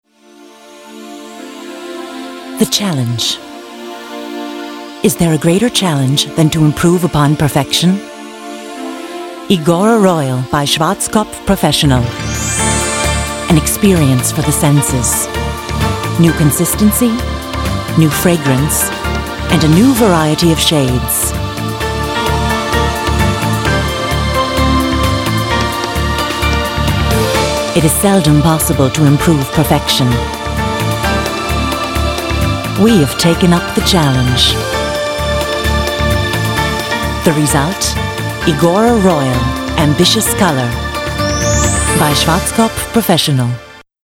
Sehr vielseitige Stimme - von warm bis hell; von mittel-tief bis hoch.
englische Sprecherin.
Sprechprobe: Industrie (Muttersprache):
English (US), female voiceover artist.